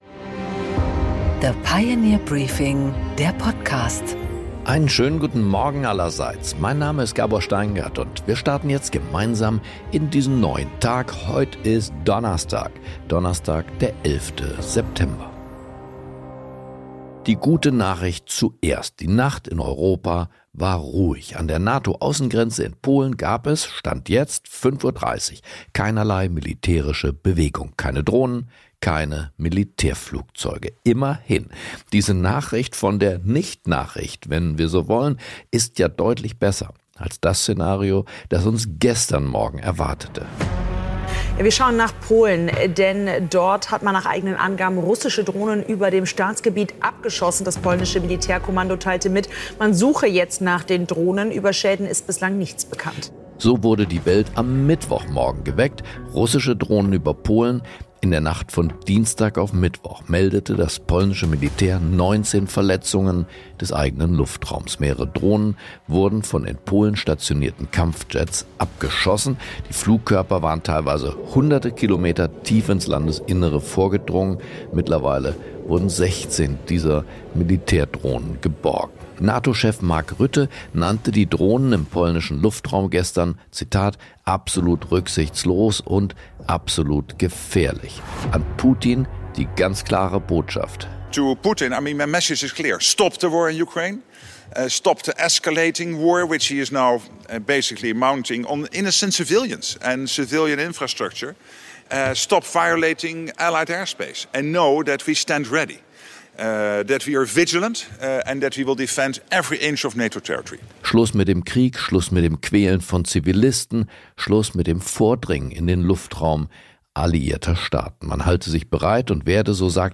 Gabor Steingart präsentiert das Pioneer Briefing
Im Gespräch: Jörg Kukies, ehemaliger Bundesfinanzminister und Ex-Goldman-Sachs-Manager, spricht mit Gabor Steingart über die wirtschaftliche Lage Europas, den Reformstau in Paris und Berlins ökonomische Schwäche.